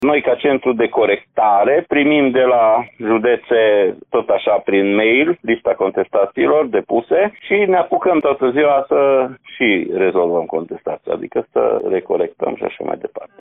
Inspectorul școlar general al județului Mureș, Ștefan Someșan.